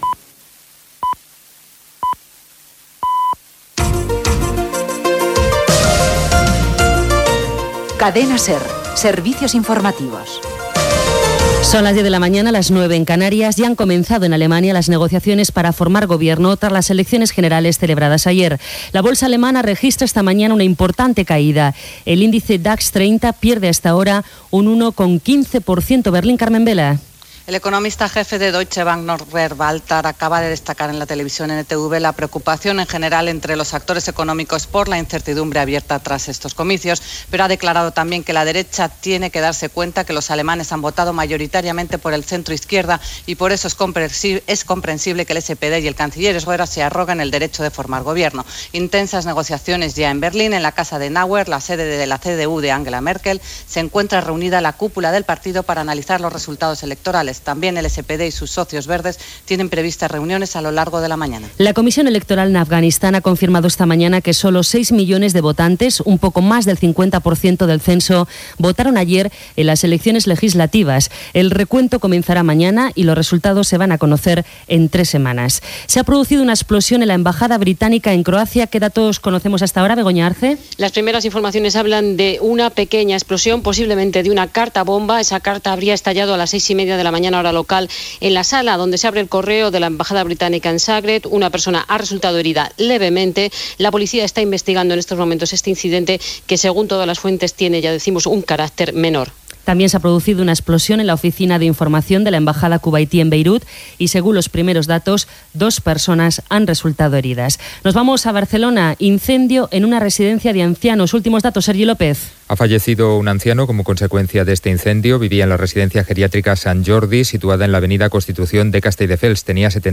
Senyals horaris, eleccions alemanyes, eleccions legislatives a Afganistan, Croàcia, incendi en una residència de Castelldefels, "El pulsómetro", publicitat, dona pas a Carles Francino per continuar l'espai "Hoy por hoy", el fracàs escolar, indicatiu de l'emissora
Informatiu